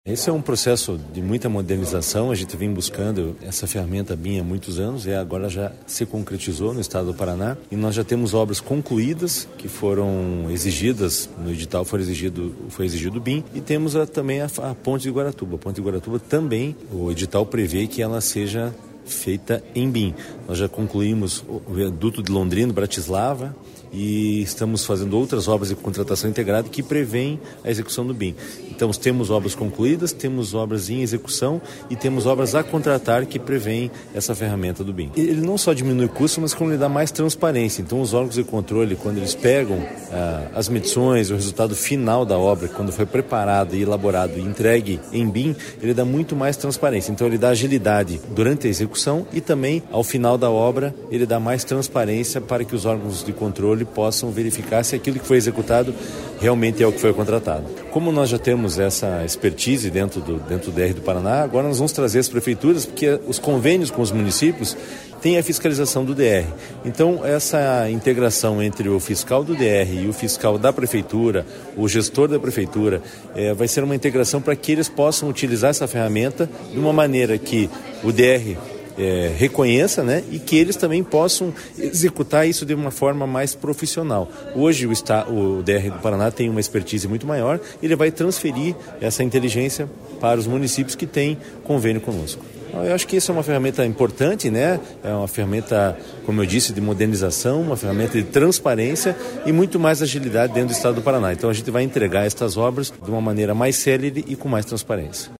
Sonora do diretor-presidente do DER, Fernando Furiatti, sobre a capacitação de servidores municipais para aplicação da metodologia BIM